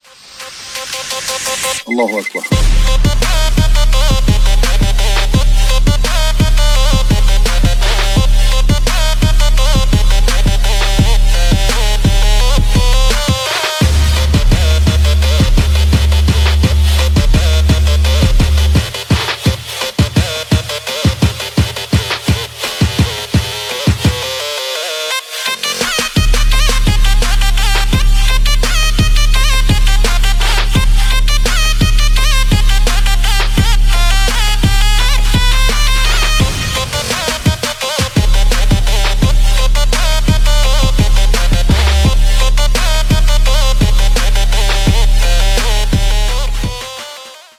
trap remix